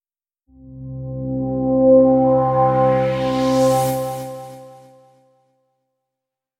Space And Time Transition Sound Effect Free Download